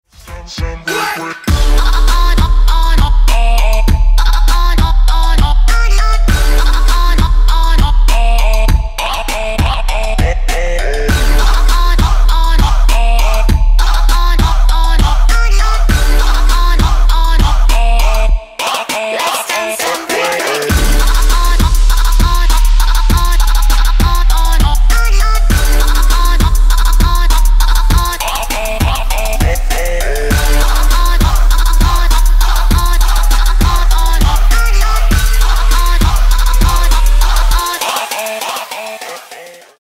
Trap
трэп